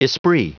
Prononciation du mot esprit en anglais (fichier audio)
Prononciation du mot : esprit